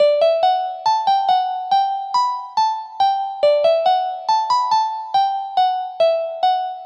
Tag: 140 bpm Rap Loops Piano Loops 1.15 MB wav Key : Unknown